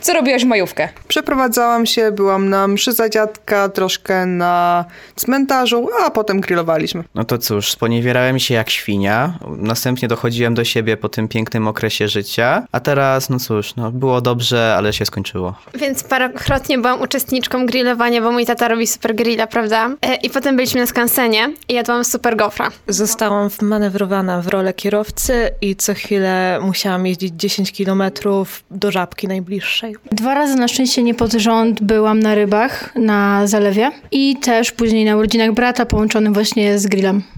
Grill, góry czy zaległości? Sonda studencka o majówce
sonda-majowka.mp3